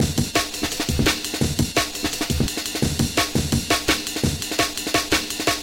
Now let’s hear the 3 new edits together with the original, combined to make a 4 bar section of drums: